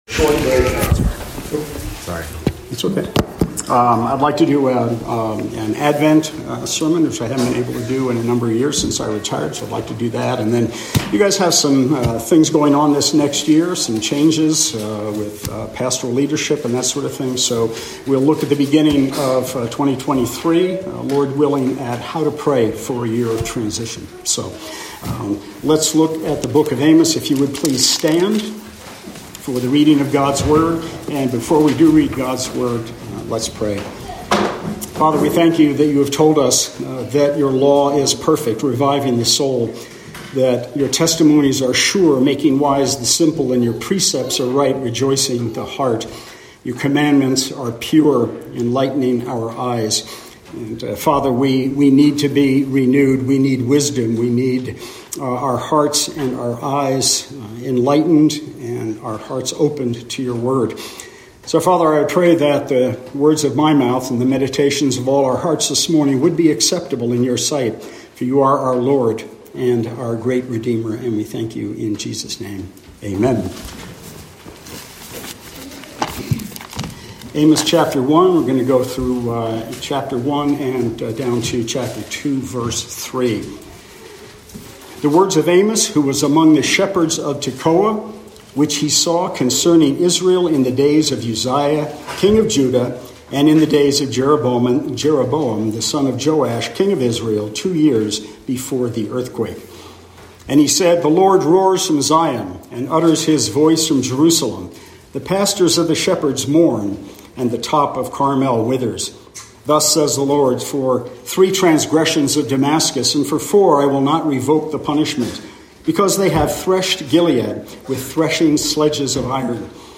Amos 1:1-2:3 Service Type: Morning Service The justice of God's law is not just for his people